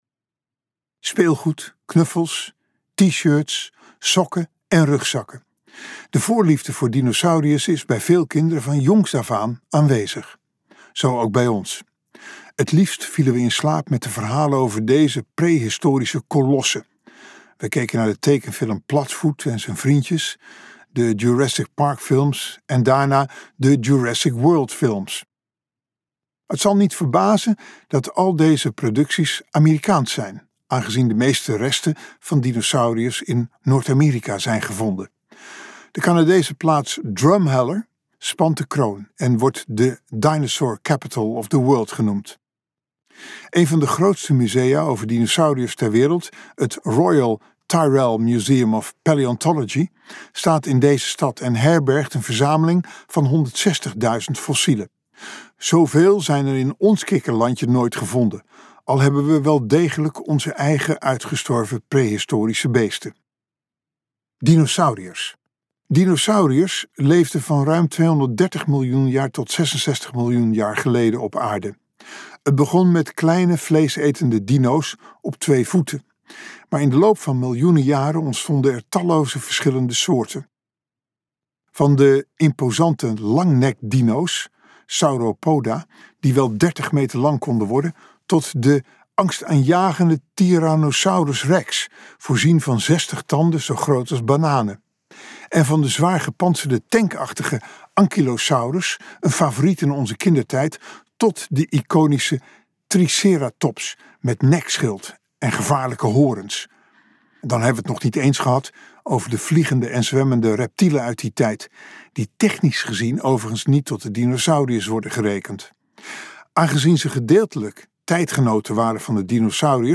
Ambo|Anthos uitgevers - Een klein land vol geschiedenis luisterboek